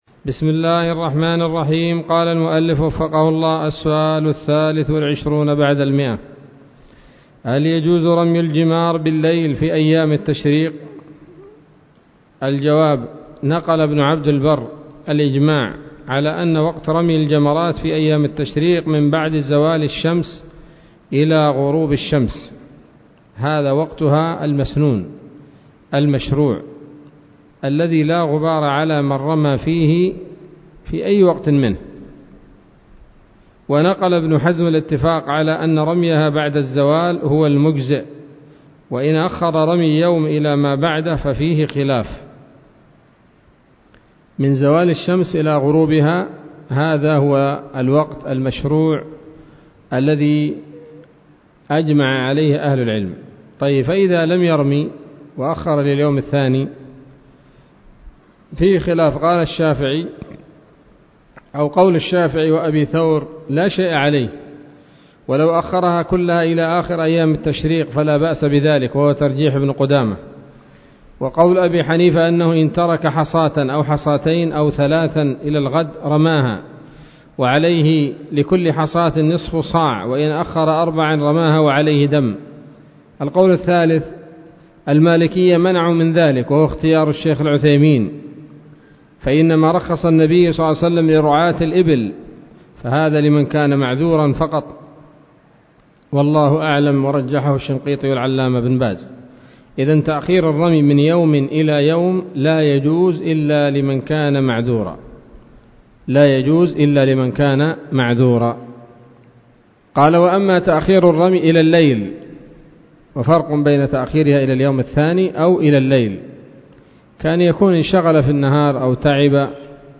الدرس الرابع والأربعون من شرح القول الأنيق في حج بيت الله العتيق